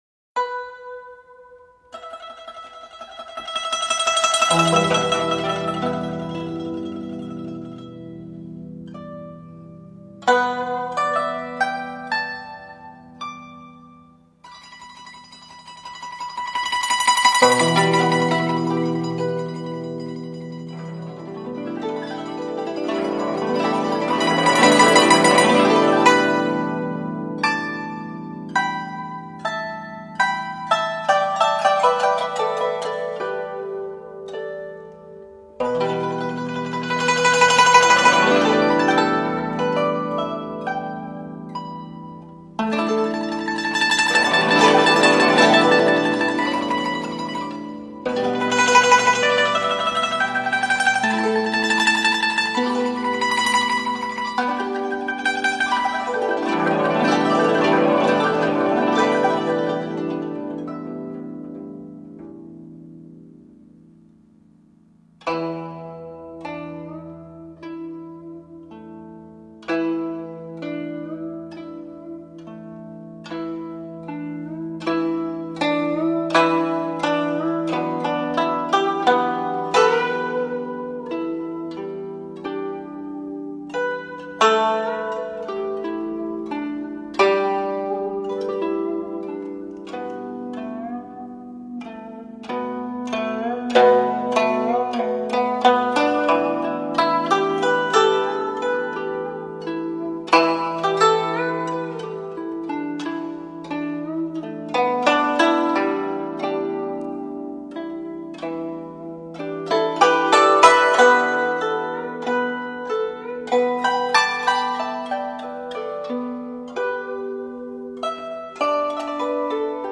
雪山春晓 古筝曲
此曲旋律优美，风格独特，内容丰富，感染力强。